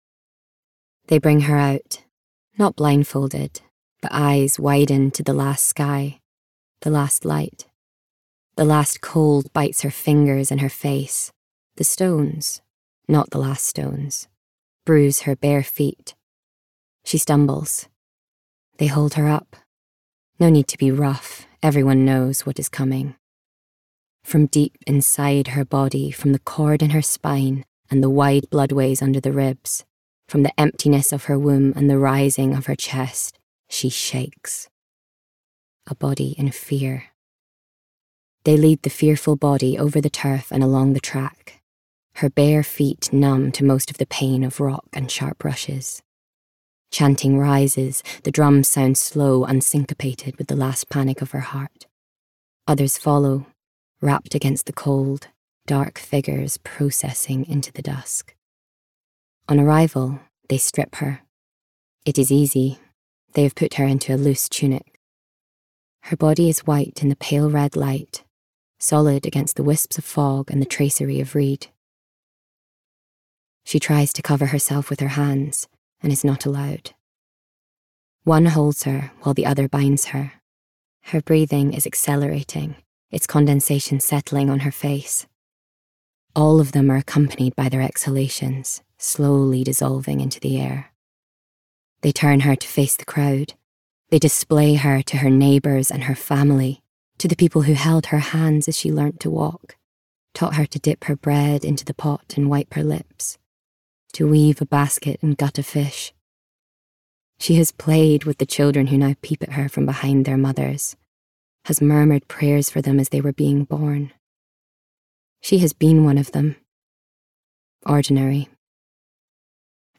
Voice Reel
Audiobook 1 - Descriptive, Atmospheric, Dark